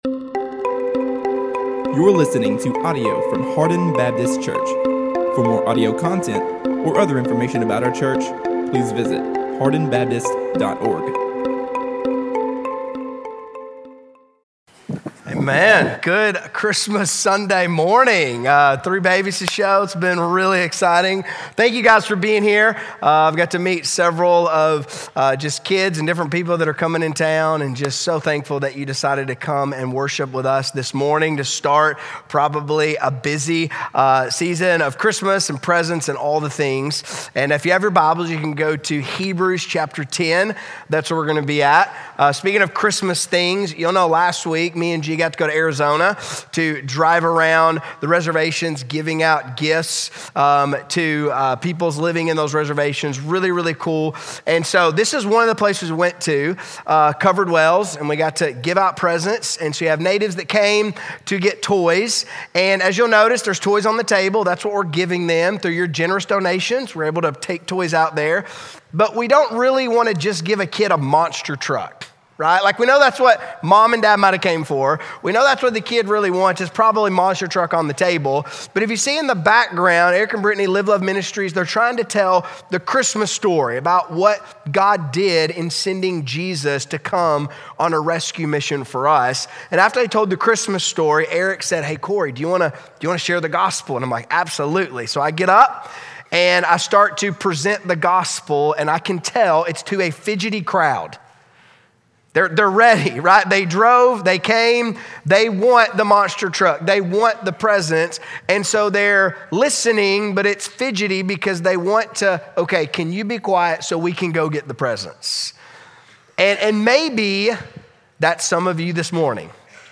Sermons by Series – Hardin Baptist Church